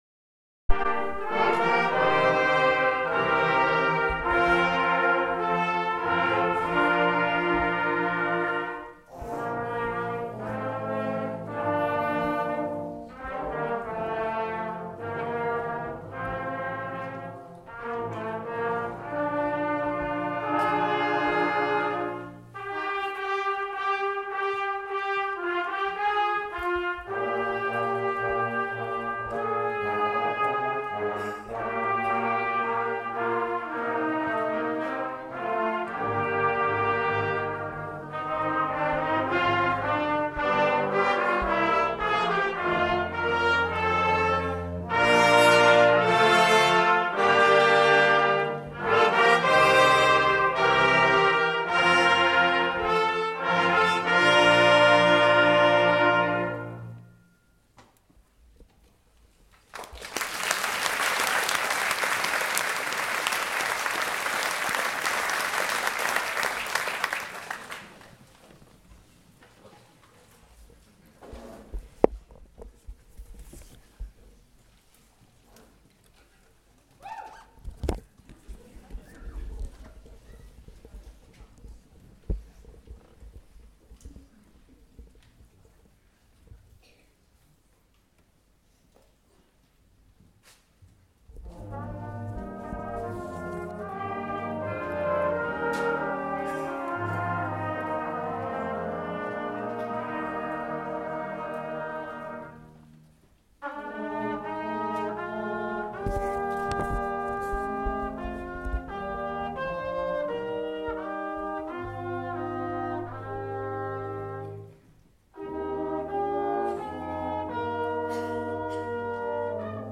brass ensemble